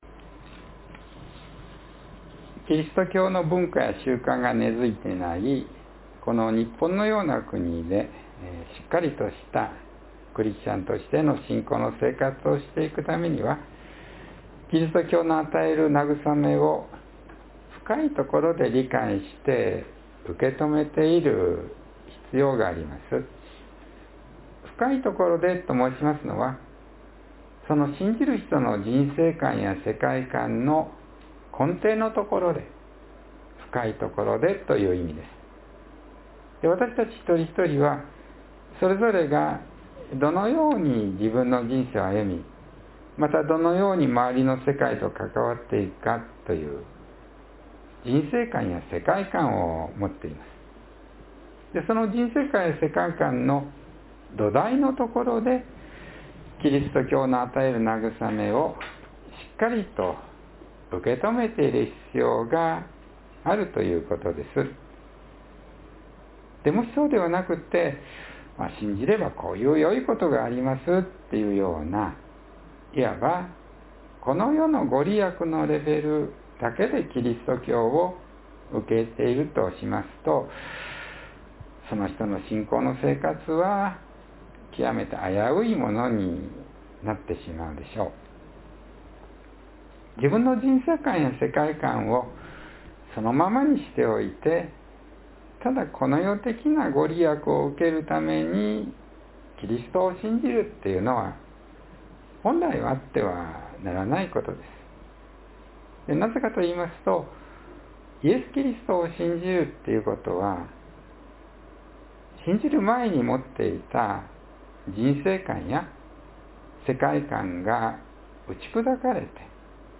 （12月28日の説教より）